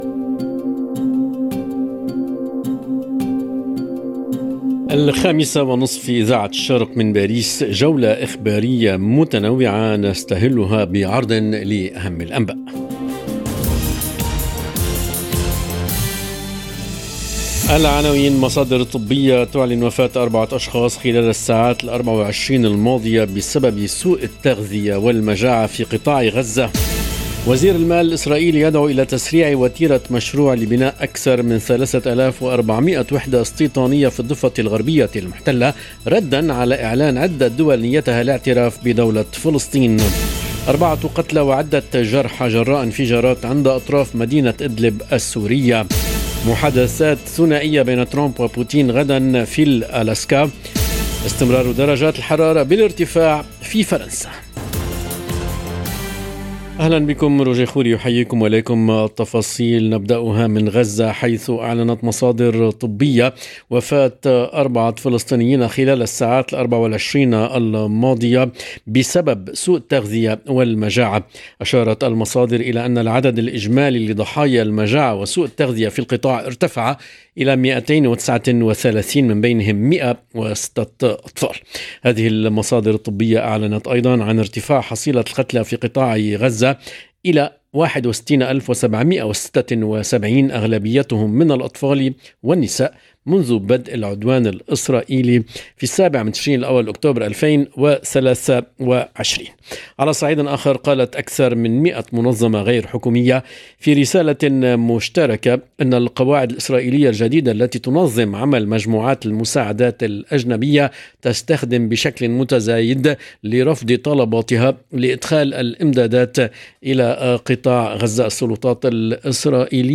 نشرة أخبار المساء : مصادر طبية تعلن وفاة 4 اشخاص خلال الساعات الـ24 الماضية بسبب سوء التغذية والمجاعة في قطاع غزة - Radio ORIENT، إذاعة الشرق من باريس